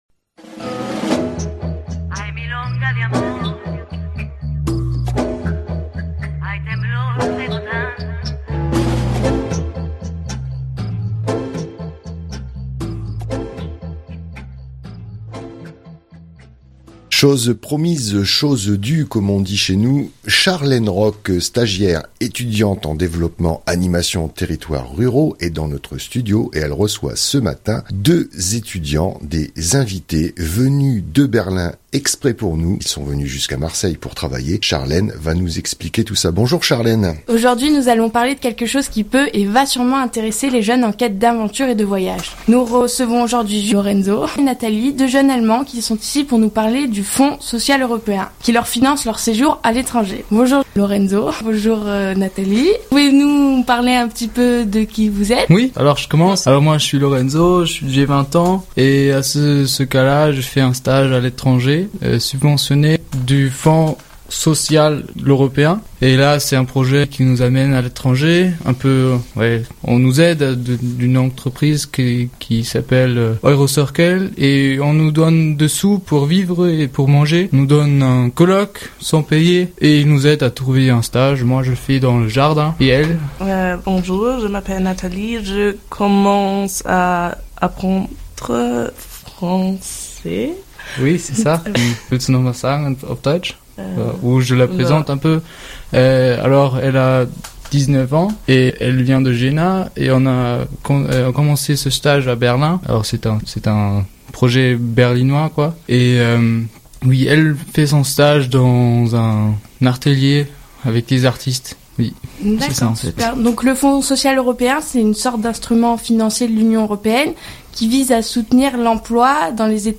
deux jeunes berlinois dans notre région